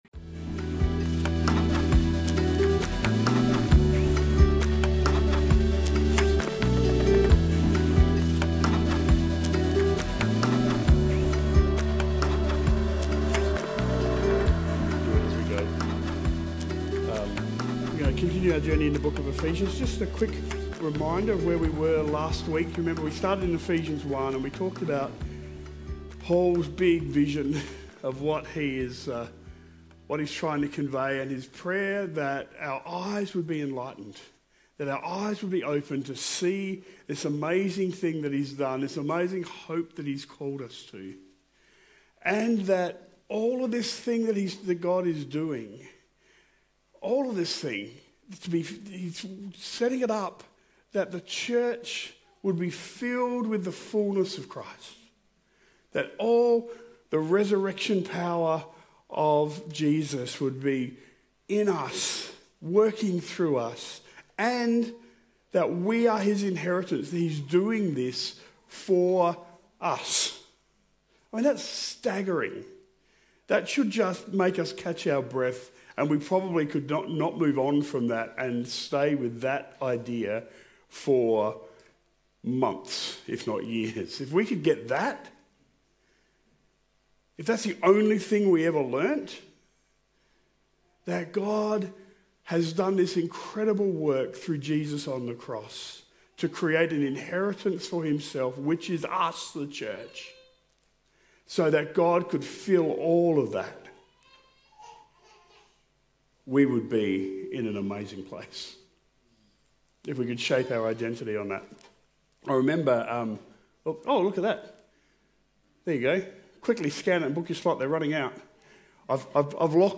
Today’s sermon on Ephesians 2 explores reconciliation through Christ: from spiritual death to life by grace.